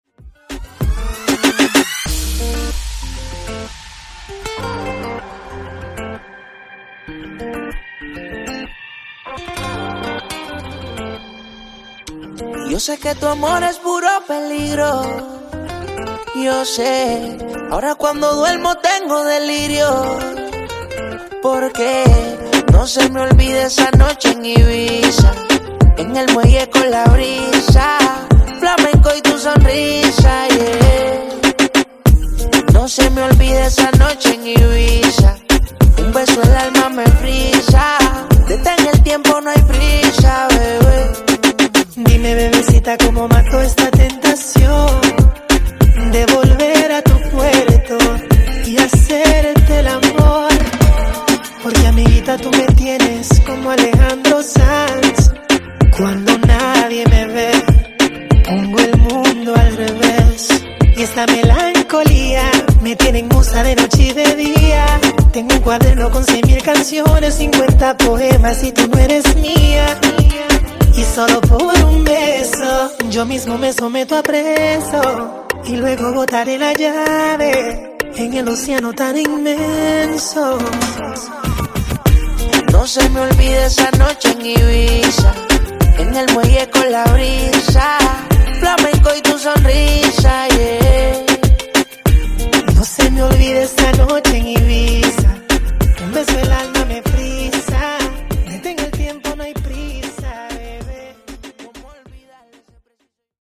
Genre: RE-DRUM
Dirty BPM: 80 Time